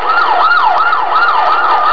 Sound FX
siren1
siren1.wav